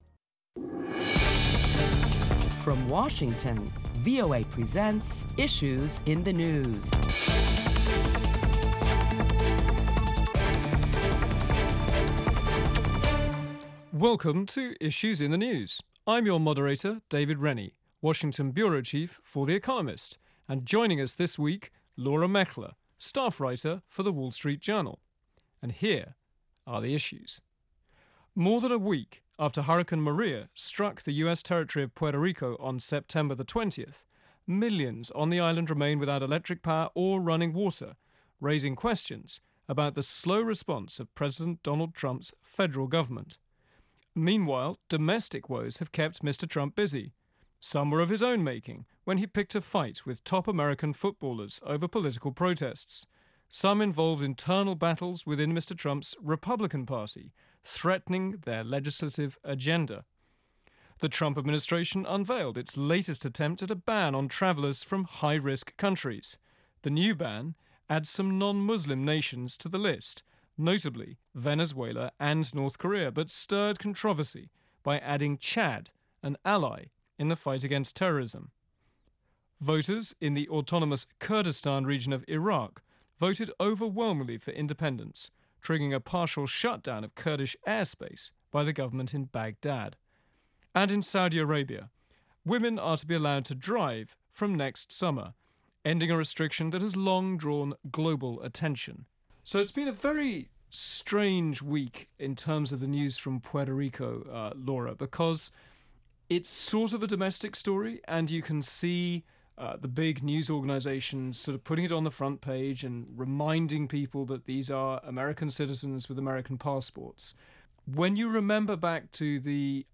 Listen as prominent Washington correspondents discuss the latest issues in the news, including U.S. response to the hurricane disaster in Puerto Rico and separatist movements in Spain and Iraq.